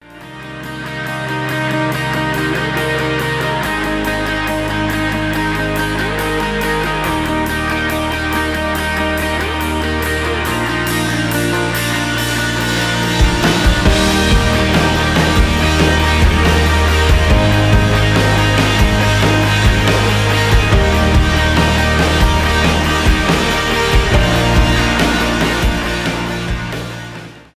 инструментальные
без слов
alternative rock